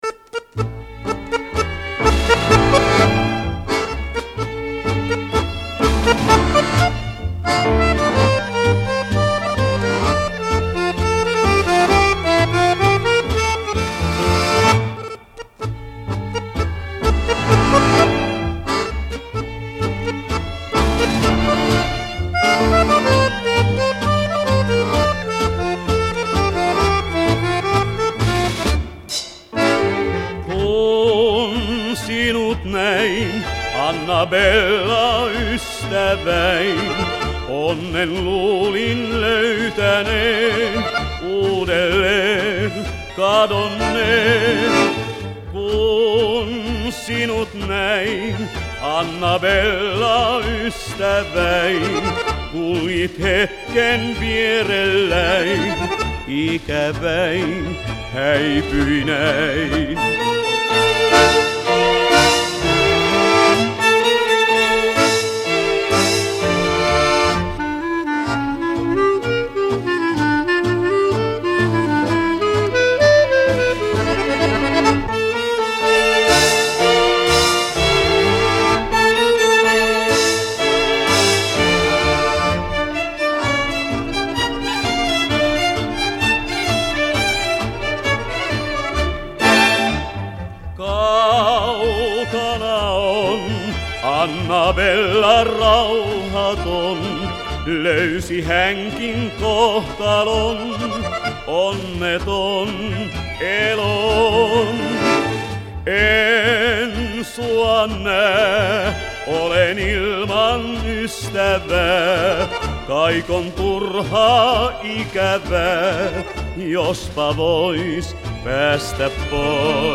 Начну с танго....